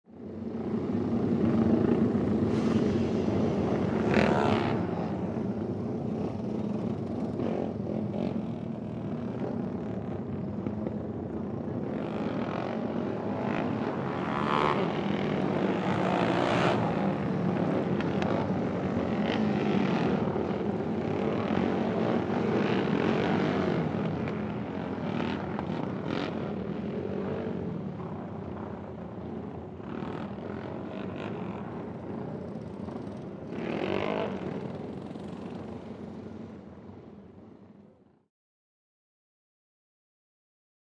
Motorcycle; Several Four Stroke Dirt Bikes Ride Around Track.